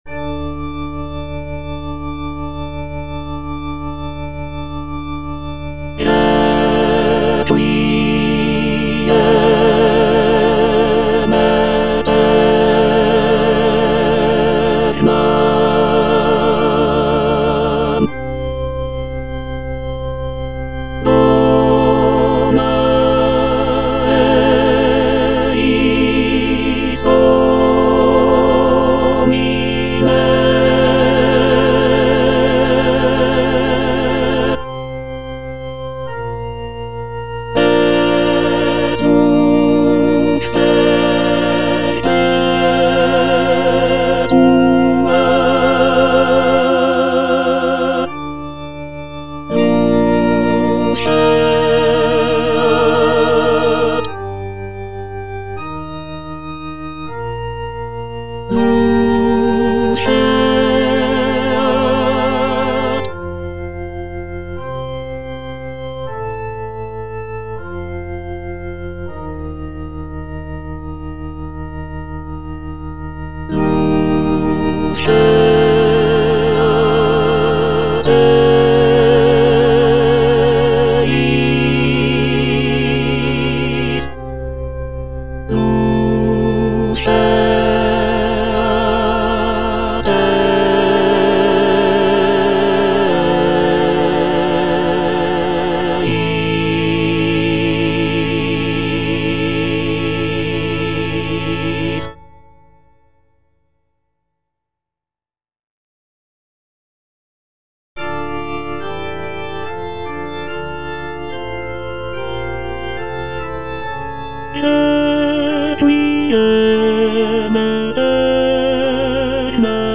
version with a smaller orchestra
(tenor II) (Emphasised voice and other voices) Ads stop